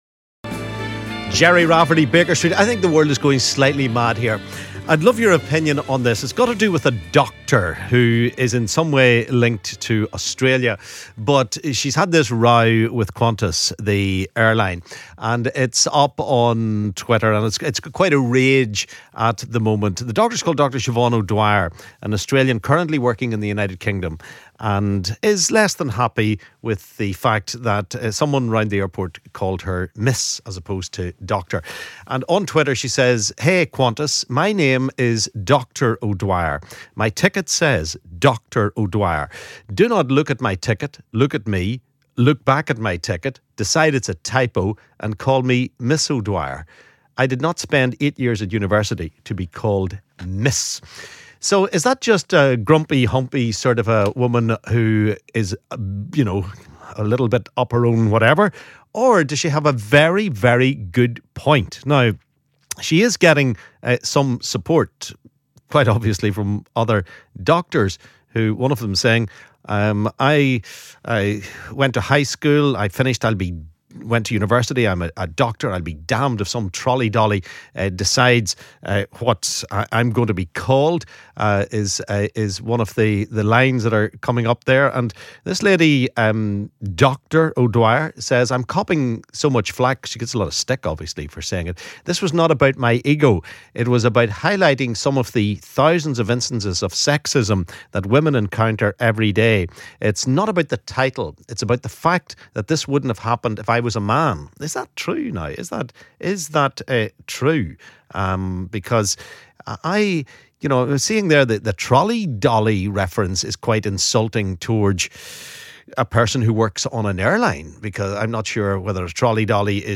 LISTEN ¦ Doctor complains after airline worker calls her 'Miss' instead of 'Dr' - our listeners react